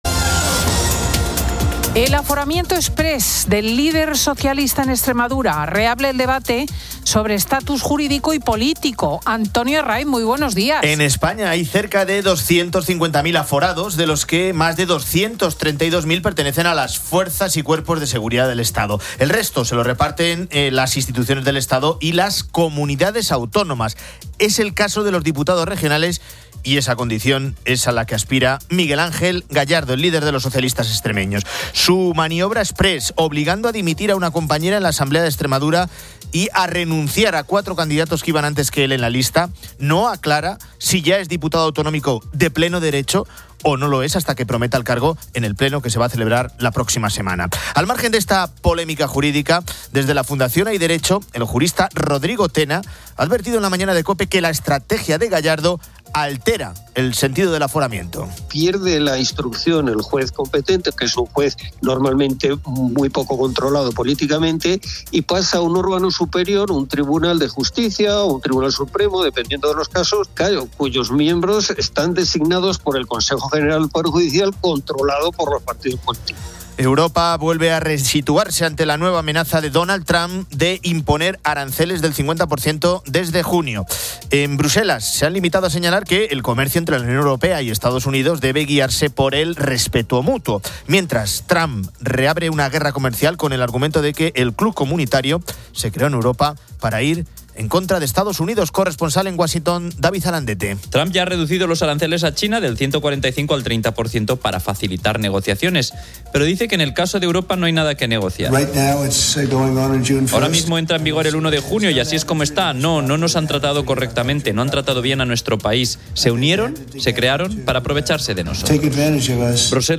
Fin de Semana 10:00H | 24 MAY 2025 | Fin de Semana Editorial de Cristina López Schlichting.